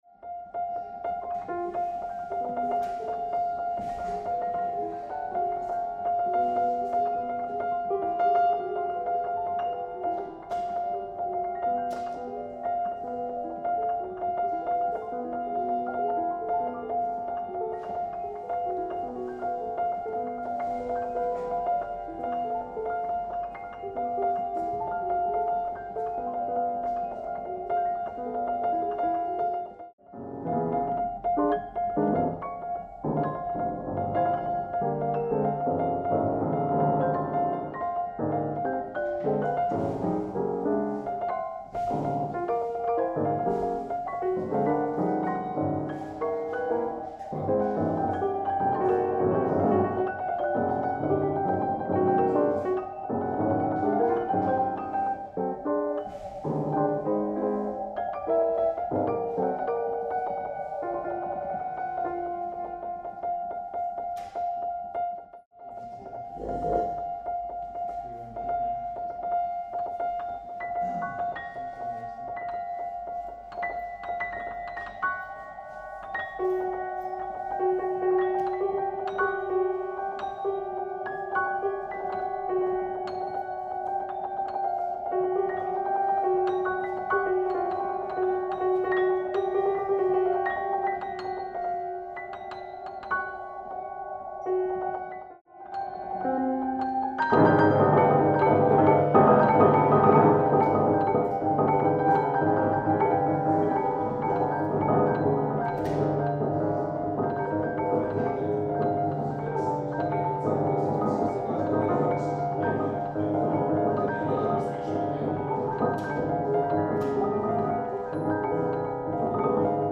Piano
Sound Installation